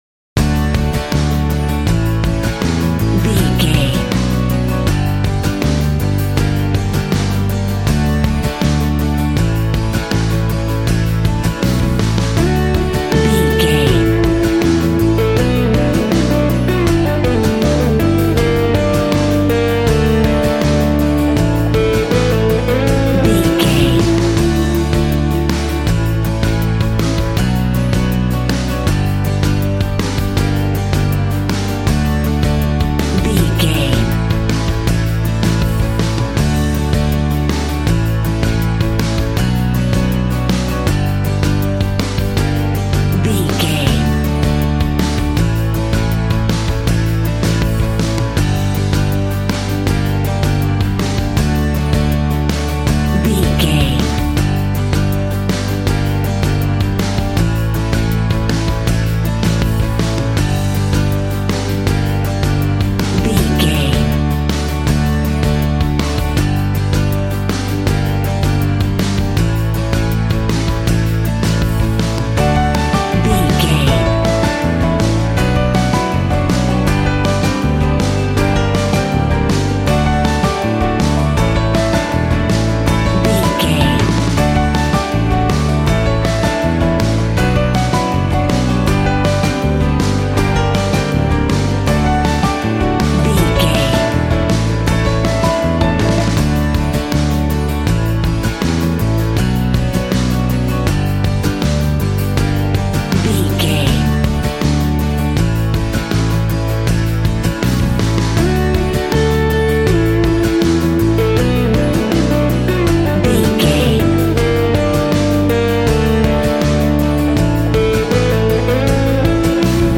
Ionian/Major
D
Fast
bouncy
positive
double bass
drums
acoustic guitar